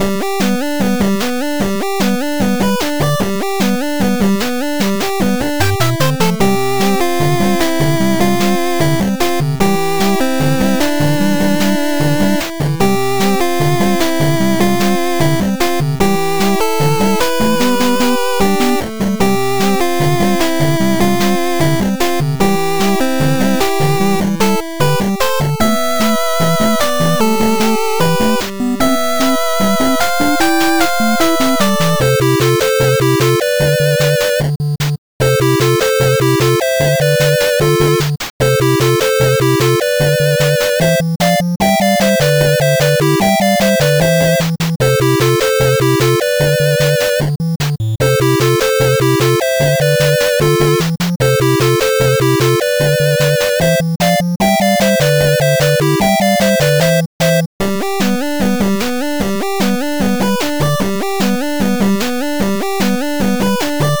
8bit music for action game.